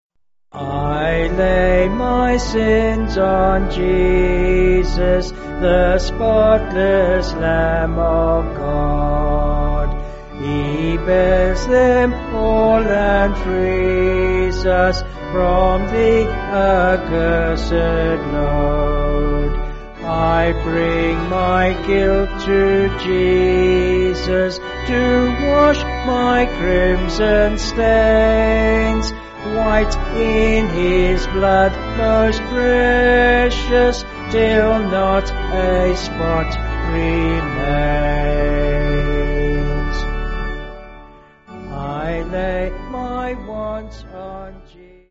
Vocals and Organ
264.5kb Sung Lyrics 2.8mb